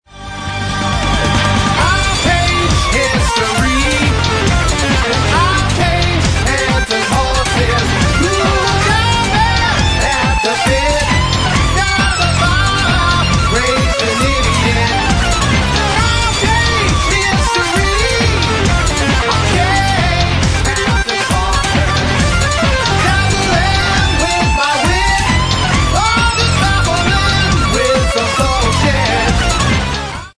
prog/symphonic/epic/metal
dynamic choruses
modern prog metal
trombone